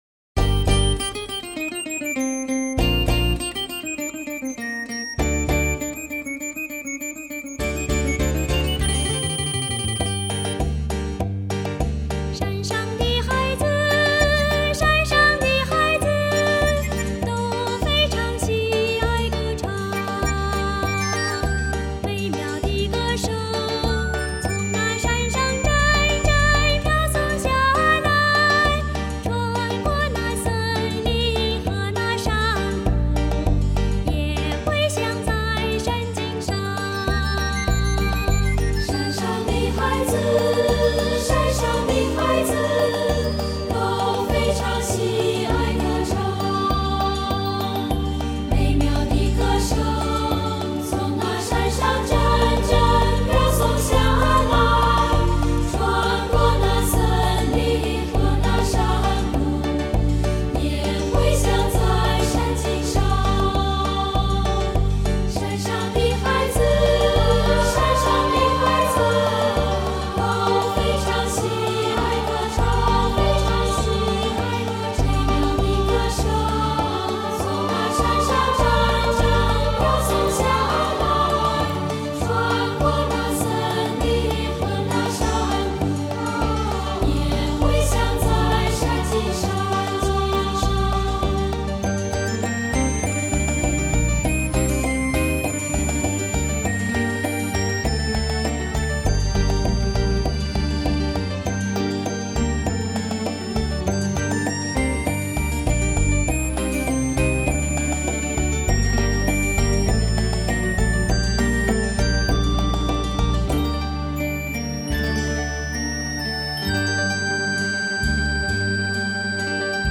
唯一受到国外乐评肯定，认定是「天使存在的最好证明」的东方合唱专辑。
荣获第9届金曲奖传艺类最佳儿童乐曲唱片奖